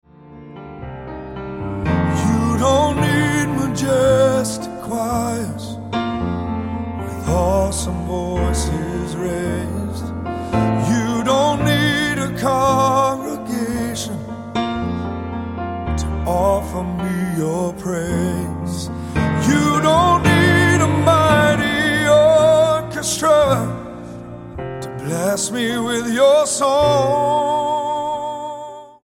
STYLE: Gospel
grand piano, the mood is downbeat throughout